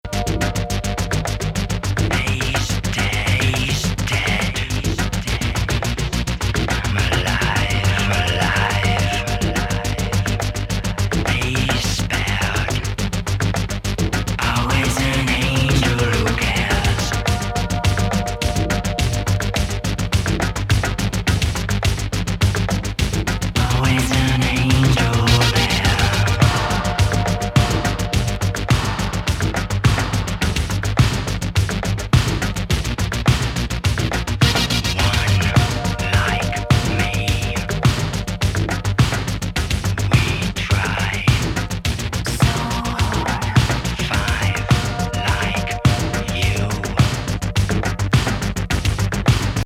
インダストリアル・ノイズ～エレクトロ・